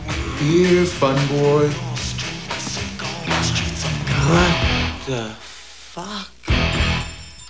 Funboy -Eric calling to Funboy in his apartment.